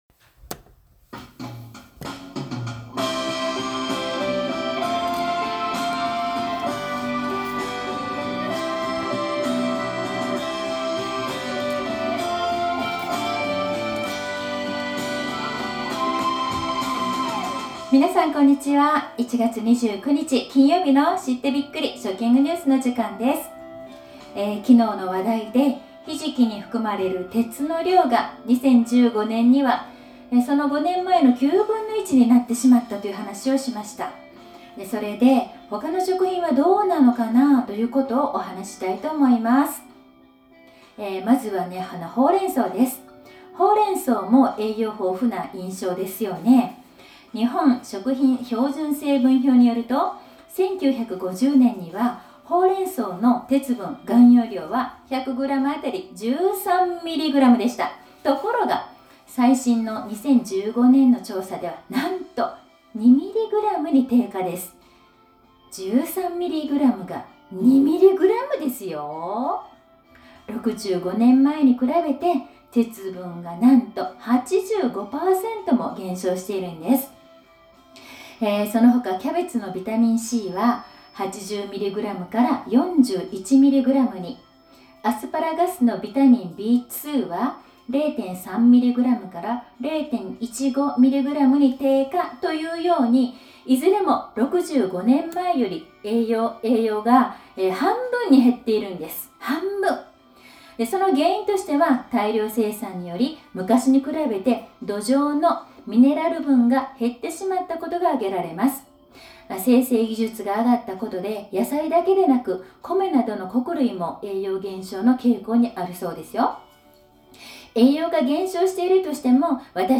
今日の食育放送では，昨日の放送内容の続きを入れて，野菜などの栄養素が減ってきている話をしました。例としてほうれん草やアスパラガスの話をしています。